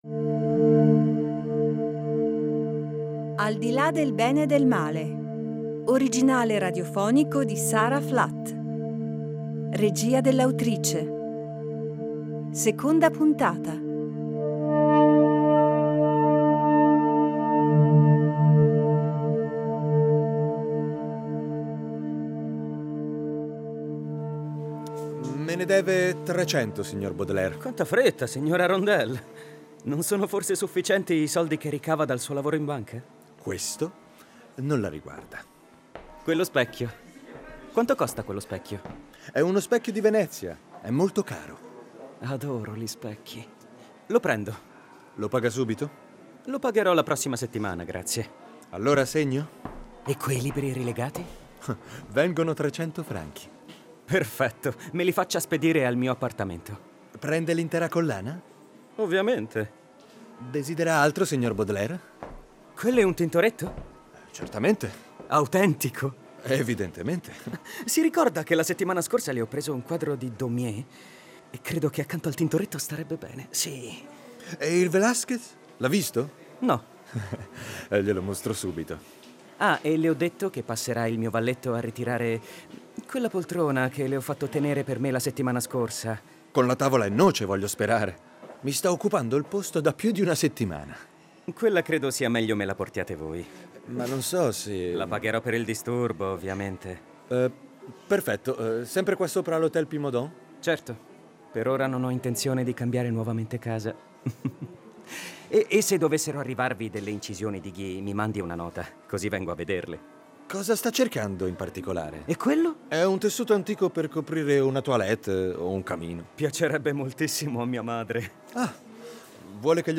Baudelaire, quell’autore che viene da sempre riconosciuto come un poeta maledetto, si rivela in questo film sonoro, in tutta la sua umanità e ecletticità.